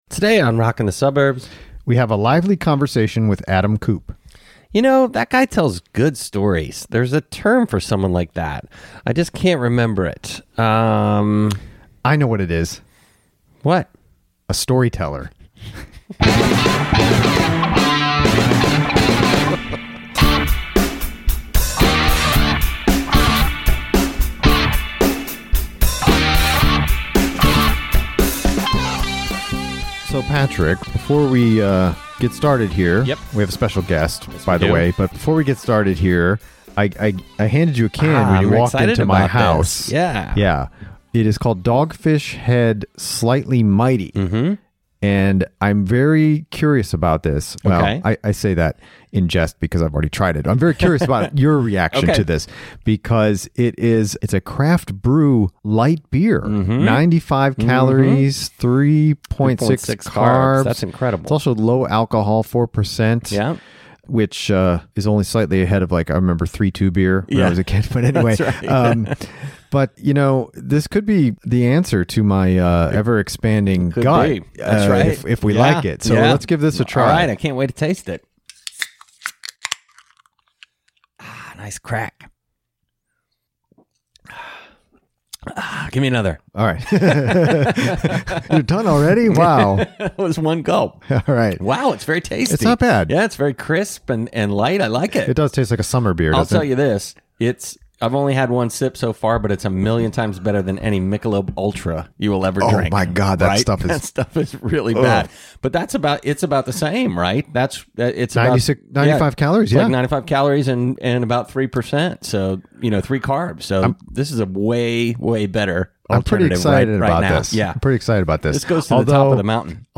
via Skype about a super cool Raconteurs show he got to attend at Third Man Records in Nashville.